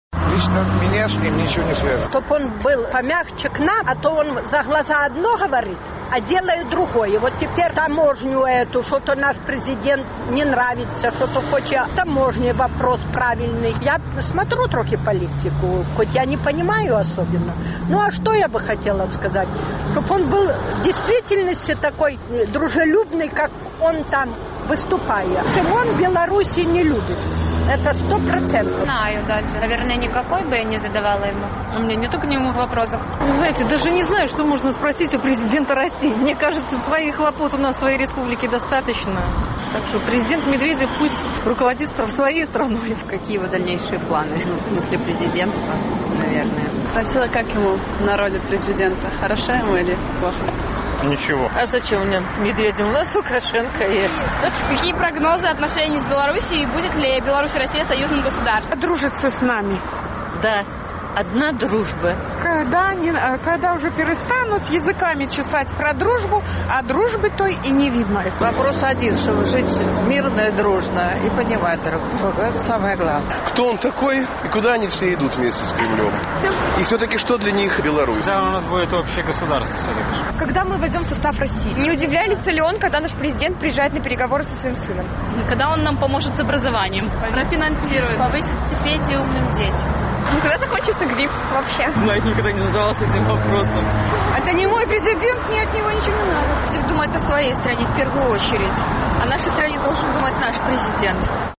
Апытаньне на вуліцах Менску: Пра што б Вы запыталіся ў Дзьмітрыя Мядзьведзева?